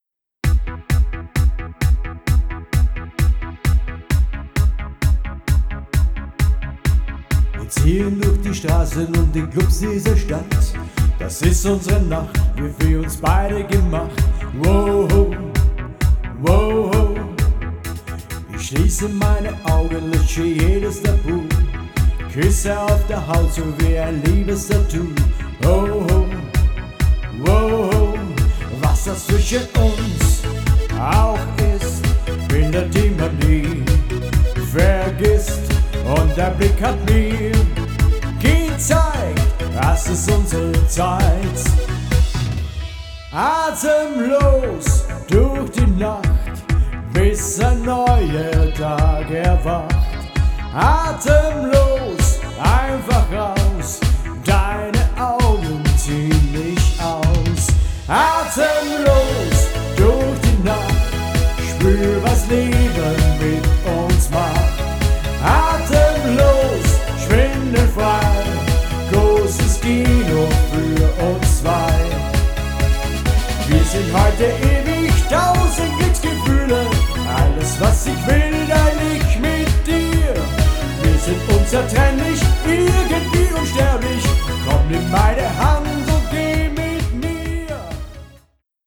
Schlager & Apres Ski & Party